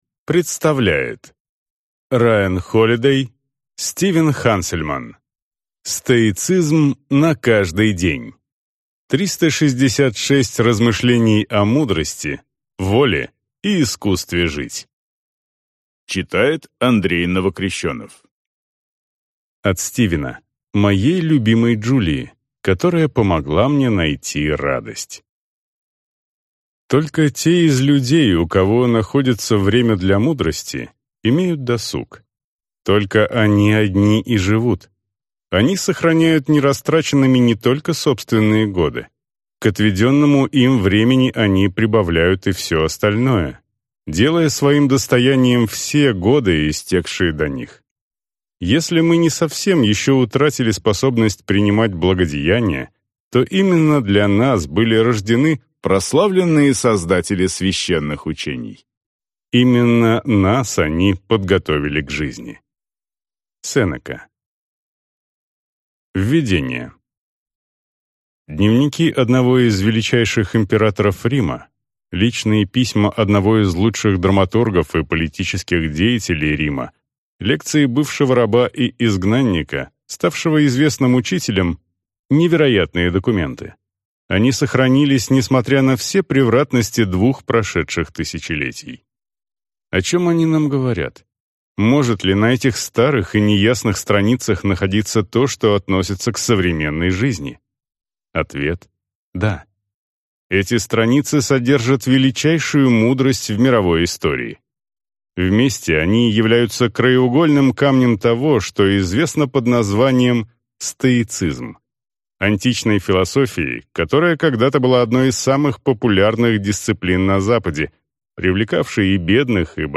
Аудиокнига Стоицизм на каждый день. 366 размышлений о мудрости, воле и искусстве жить | Библиотека аудиокниг